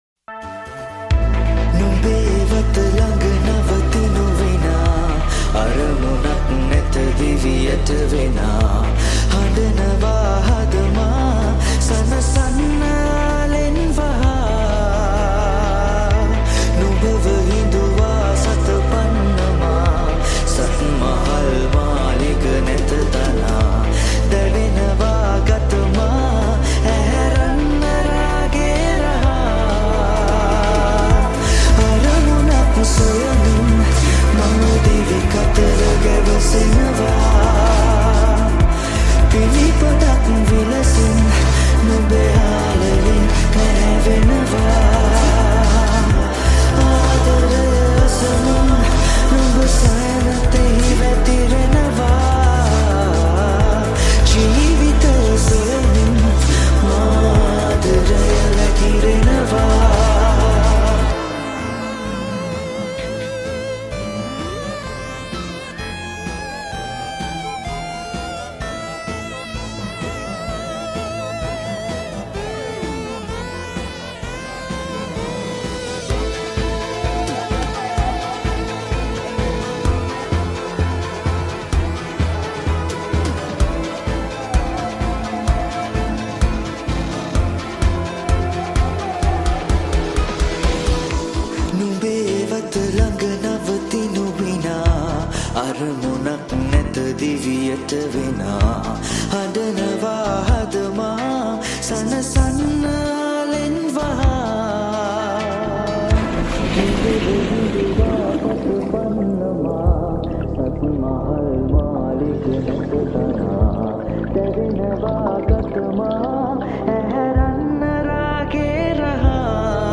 Vocal Recording